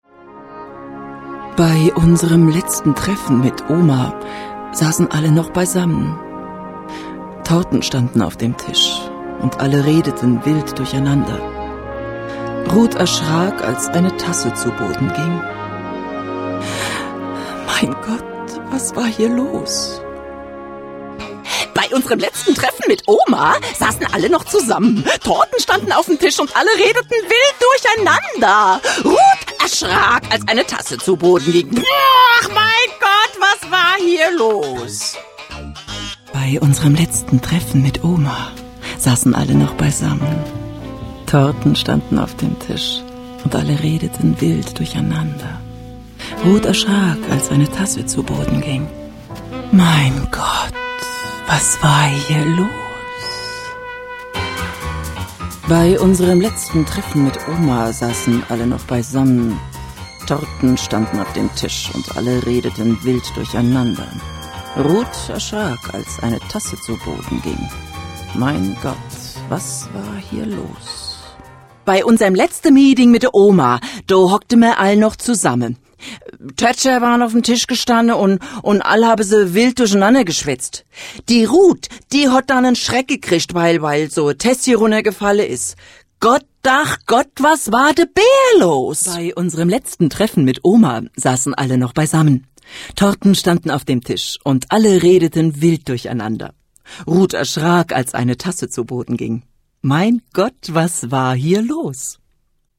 deutsche Sprecherin.
Kein Dialekt
Sprechprobe: Industrie (Muttersprache):
german female voice over artist.